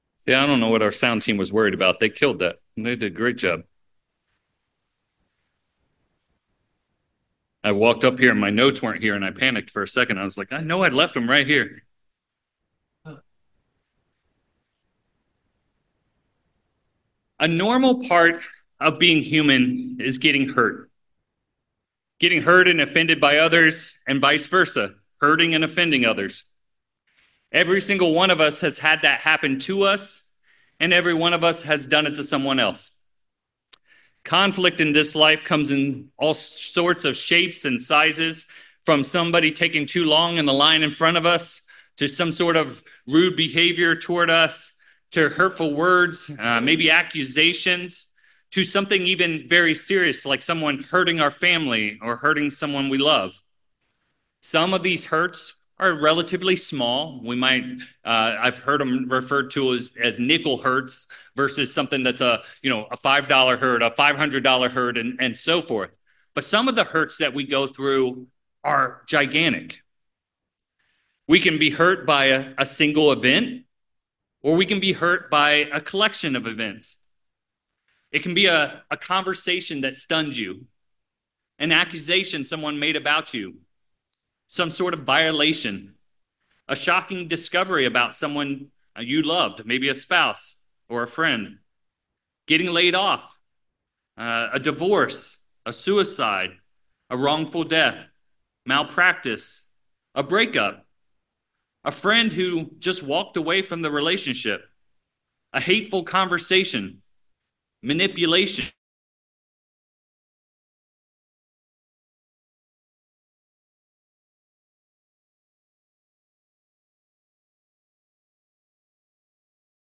In this first sermon of the series, we dive in Forgiveness. We look at God’s forgiveness toward us and His command that we must forgive others. We see that forgiveness is both a decision and a process.